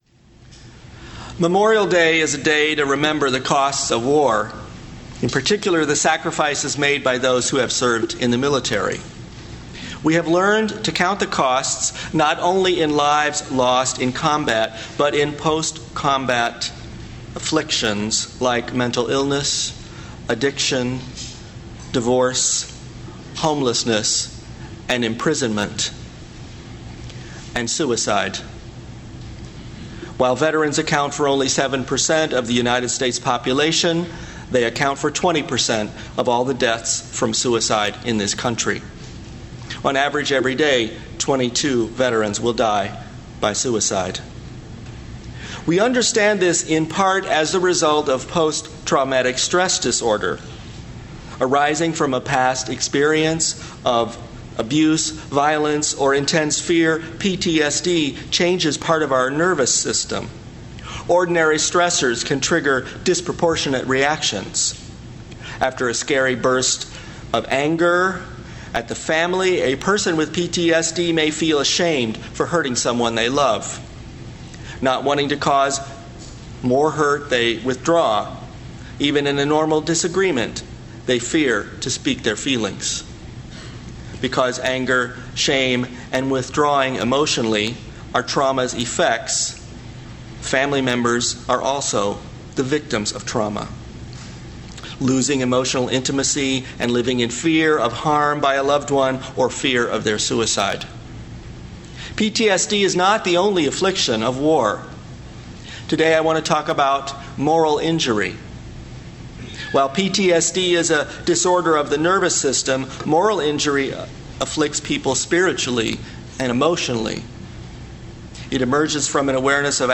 Moral Injury & Soul Repair—A Sermon for Memorial Day Sunday